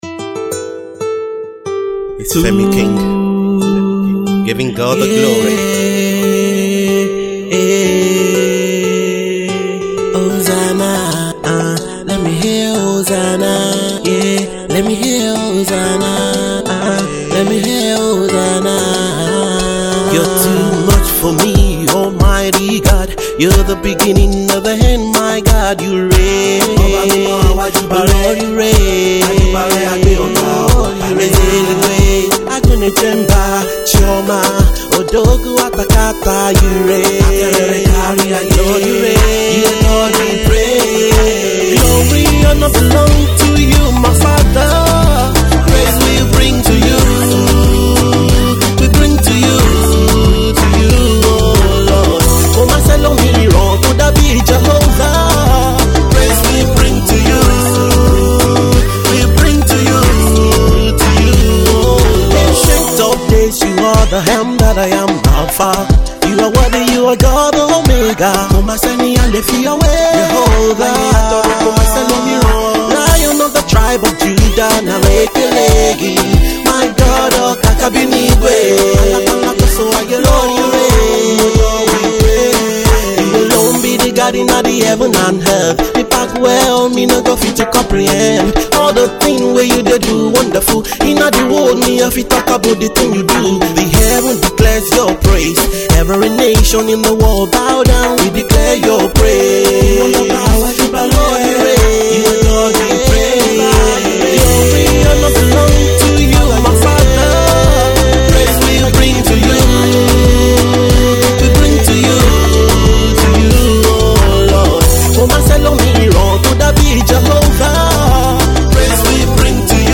a song of praise